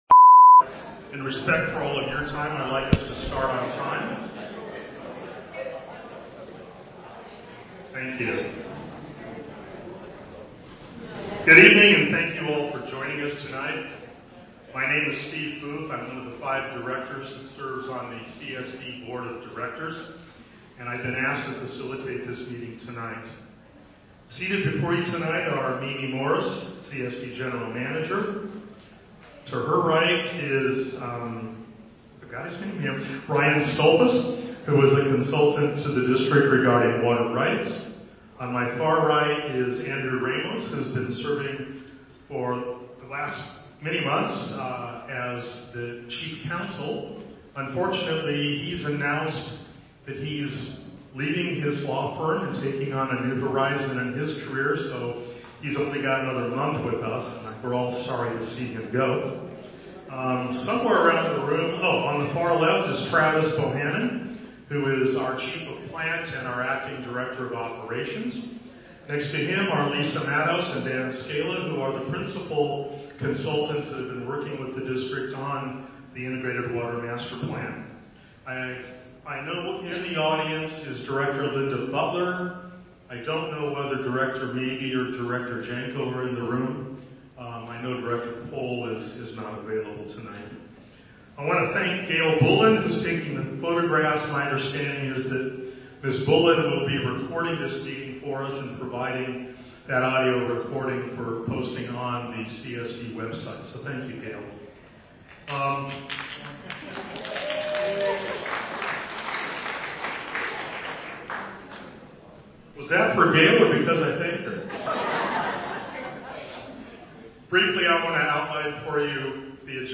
townhall+zoom+5.30.24.mp3